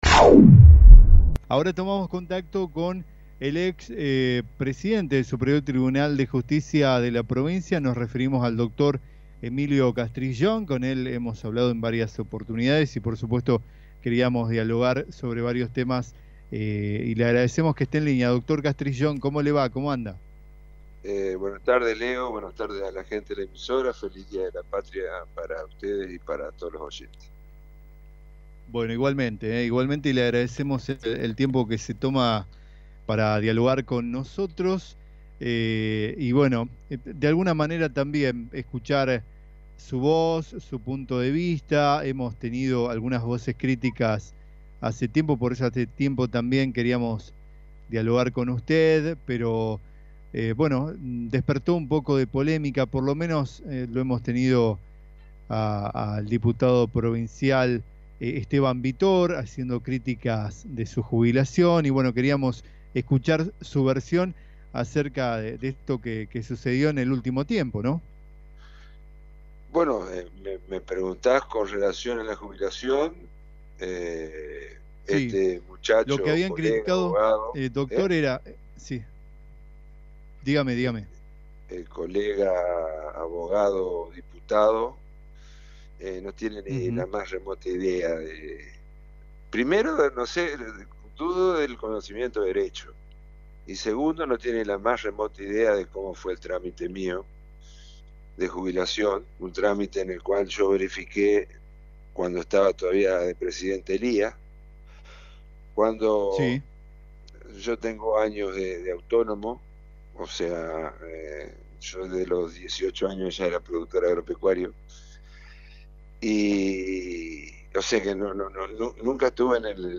El ex vocal Emilio Aroldo Castrillón logró jubilarse en tiempo récord. En Radio Victoria el ex integrante del Superior Tribunal de Justicia, explicó que estaba en condiciones de hacerlo, y con los porcentajes correctos, pero reconoció que apuraron su trámite “porque les molestaba”.